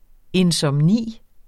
Udtale [ ensʌmˈniˀ ]